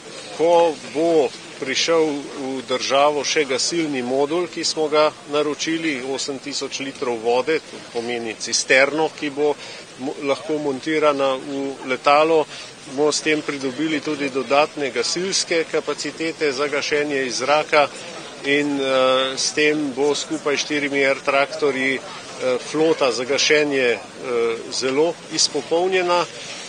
Obrambni minister Marjan Šarec ob prevzemu letala spartan